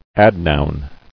[ad·noun]